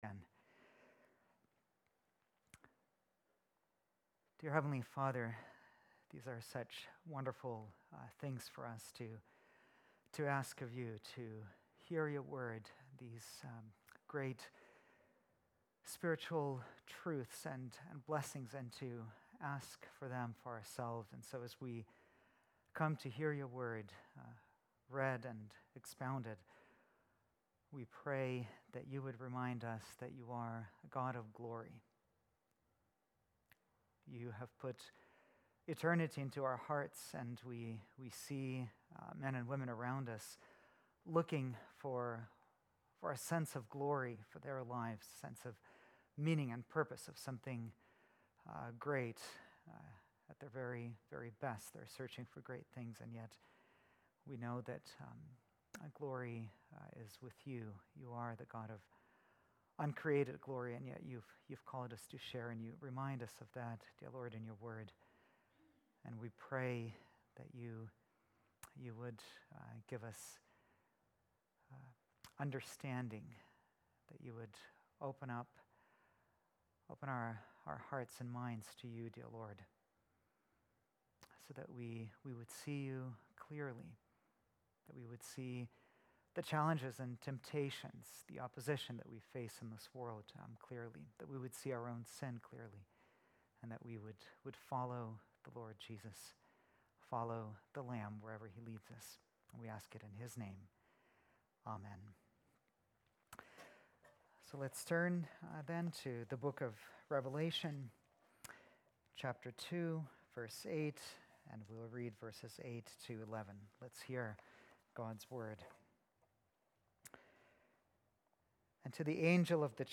March 25, 2018 (Sunday Evening)